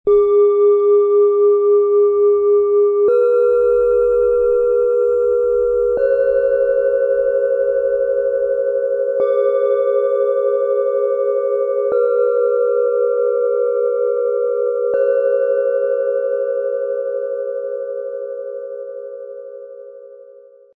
Freiheit, emotionale Tiefe und Herzöffnung - Set aus 3 Planetenschalen, Ø 11,4 -12,5 cm, 1,15 kg
Gesamtklang des Sets: Klare und weit reichende Klangräume
Entdecke den klaren und weiten Raumklang dieses einzigartigen Klangschalensets.
Erlebe Vision und Freiheit mit dem Ton von Uranus, der für seine klaren und erhebenden Frequenzen bekannt ist.
Verbinde dich mit deiner emotionalen Tiefe und Intuition durch den sanften und beruhigenden Ton des Mondes.
Tiefster Ton: Uranus
Bengalen Schale, Schwarz-Gold, 12,5 cm Durchmesser, 6,6 cm Höhe
Mittlerer Ton: Mond
Höchster Ton: DNA